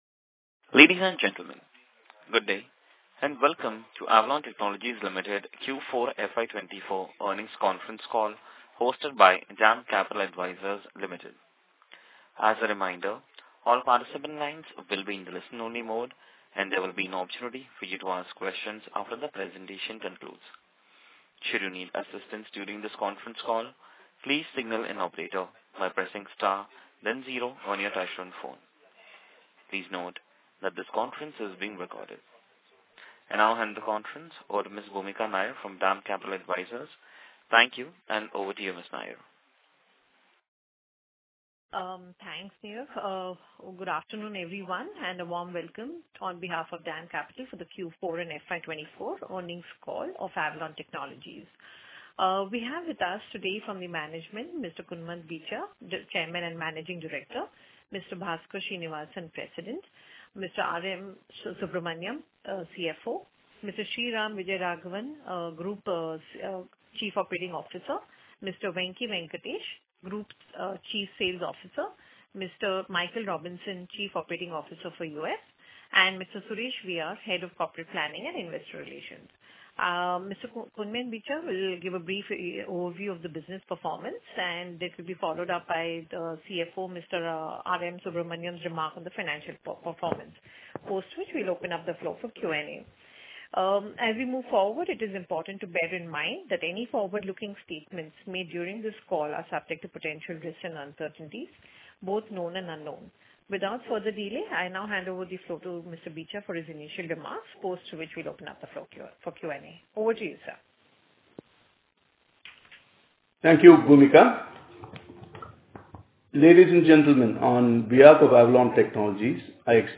Q4_FY24_Earnings_Call_Recording.mp3